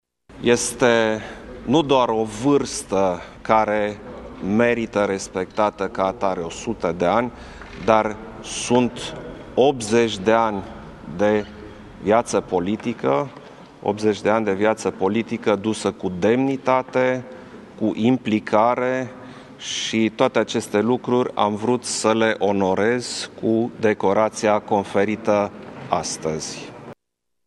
Ceremonia de la Palatul Cotroceni s-a încheiat în urmă cu puțin timp.
Președintele Klaus Iohannis a declarat la finalul ceremoniei că decorarea lui Mircea Ionescu-Quintus i s-a părut un gest firesc de apreciere: